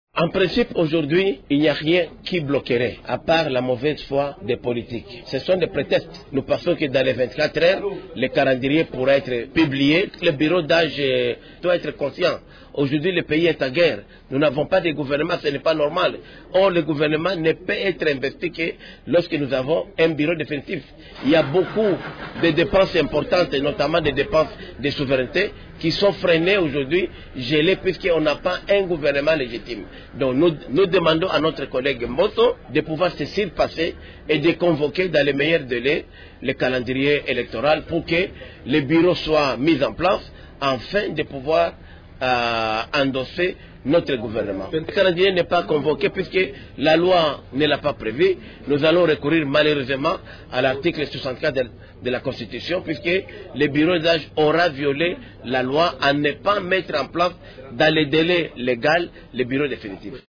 Ecoutez à ce sujet le député Willy Mishiki: